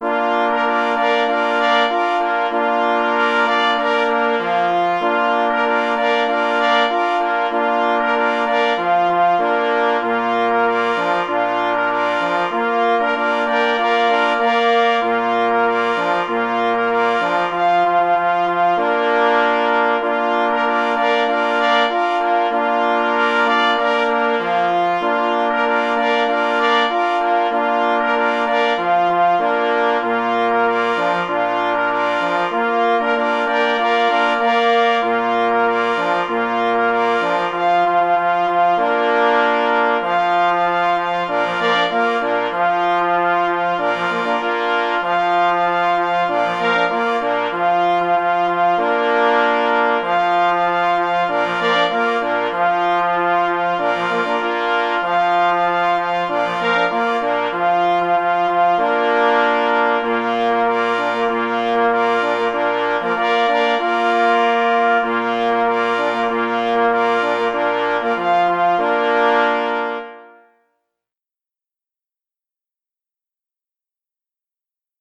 Ludowe akcenty pojawiają się również w „Marsz nr 3” na zespół naturalnych rogów myśliwskich napisanym w czerwcu 2000 […]
K. Anbild – Marsz nr 3 – na 3 plesy i 2 parforsy | PDF